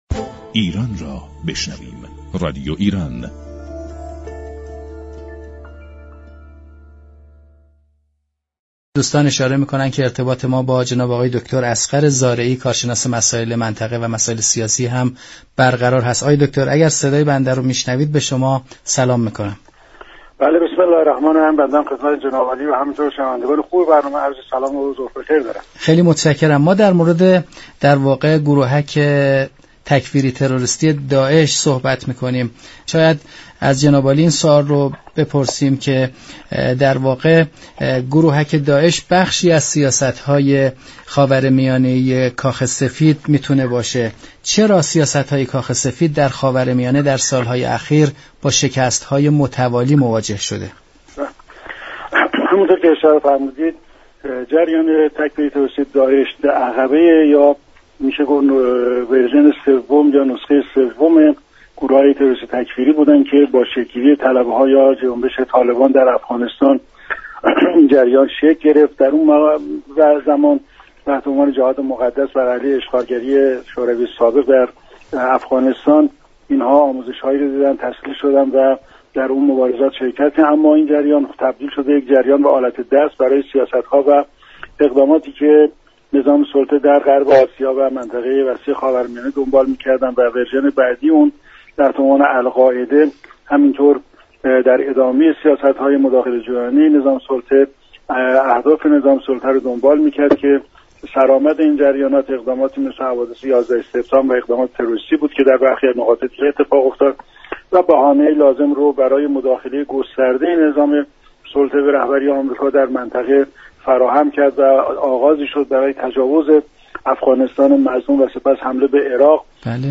كارشناس مسائل سیاسی در گفت و گو با رادیو ایران گفت: غرب و نظام سلطه به دلیل عدم شناخت و درك واقعیت های منطقه بخصوص نداشتن درك صحیح از انرژی انقلاب اسلامی و مقاومت اسلامی نتوانست به اهداف خود در منطقه خاورمیانه دست یابد.